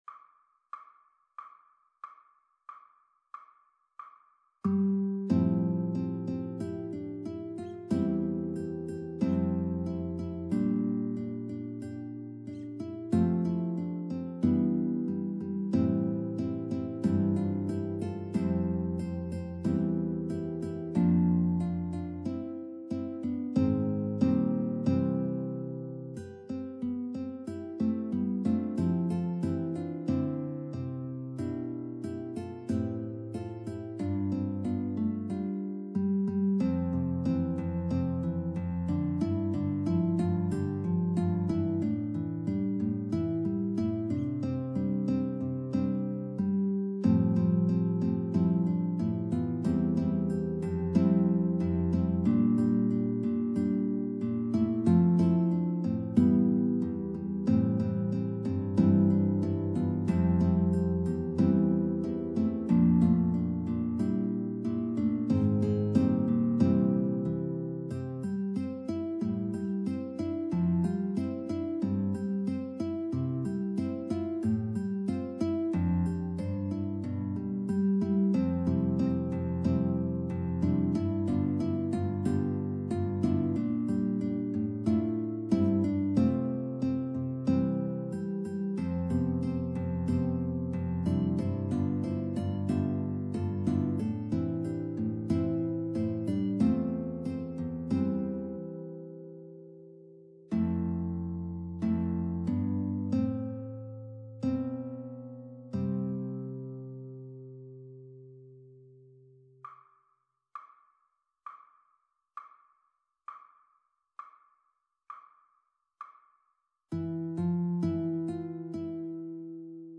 There will be an opportunity for everyone to play in a virtual guitar ensemble with a multi-level arrangement of "Variations on Simple Gifts" by Joseph Brackett Jr. for 3 guitar parts.
The backing track has all the parts, and starts with 2 measures of click.
Backing Track (mp3)
1. The variations are different tempos, so there are two measures of click in between each variation to set the player up for the new tempo.